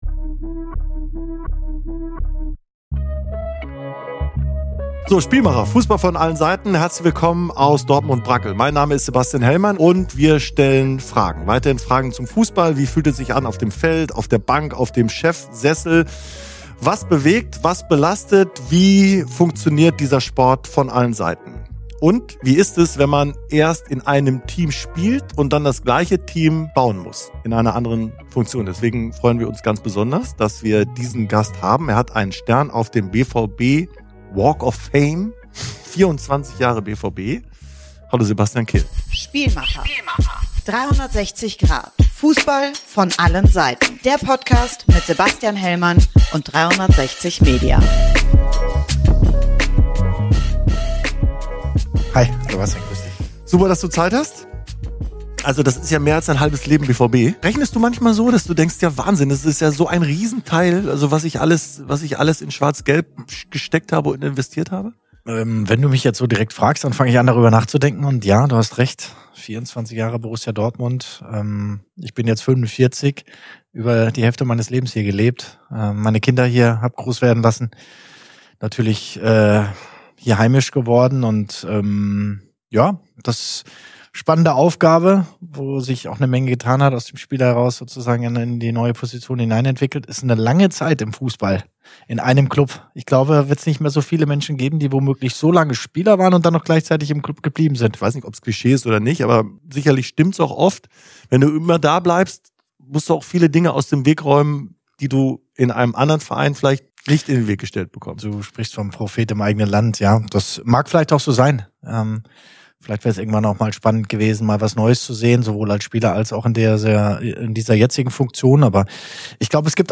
Ein sehr persönliches Gespräch über das, was es bedeutet, auf höchstem Level und mit höchsten Ansprüchen im deutschen Fußball aktiv zu sein. Es geht auch um den Umgang mit Geld, um die Balance, die man mit seinen Kindern finden muss, Ausgaben für Klamotten, Handy-Rechnungen und Urlaube - und um die Frage, was dieser Luxus eigentlich für einen Einfluss auf das eigene Leben und auf Familie hat.